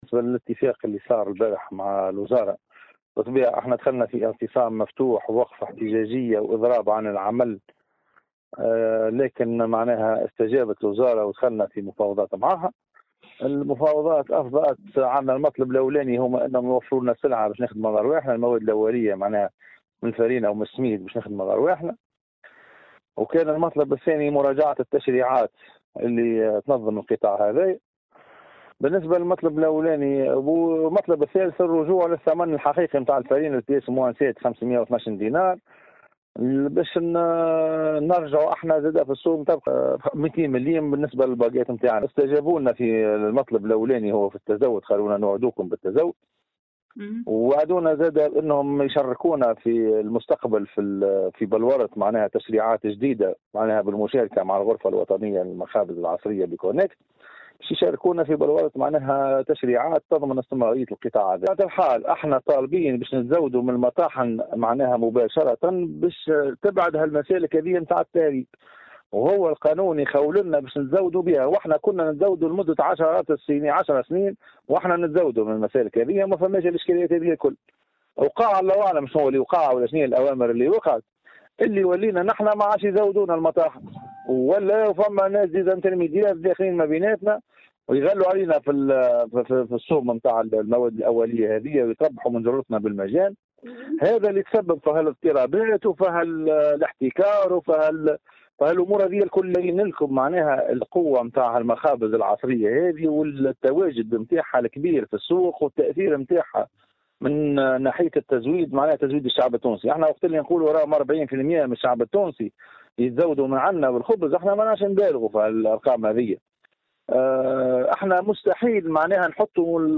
S’exprimant au micro de Tunisie Numérique